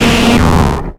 Cri de Limagma dans Pokémon X et Y.